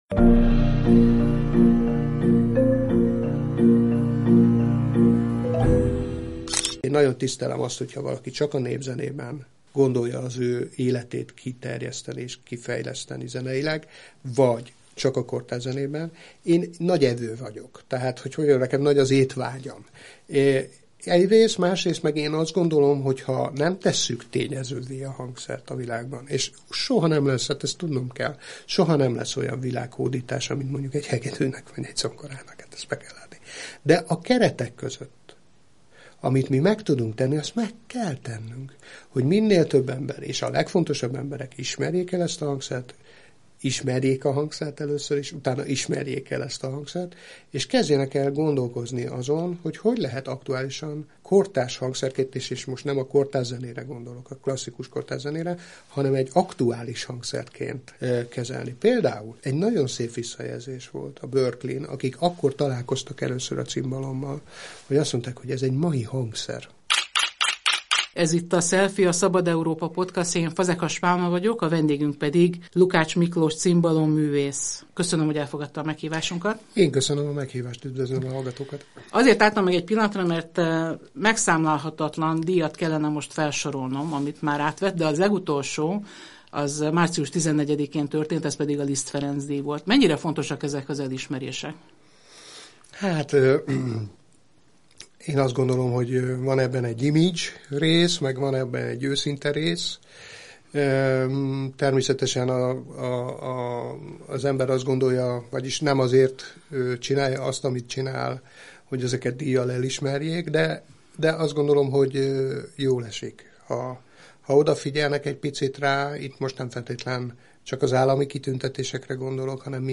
Legyen szó kortárs zenéről vagy dzsesszről, a világ legjelentősebb hangversenytermeiben koncertezik. Mesterkurzusokon mutatja meg a hangszerében rejlő lehetőségeket, hallgatói pedig rácsodálkoznak, milyen modern hangszer a cimbalom. Interjú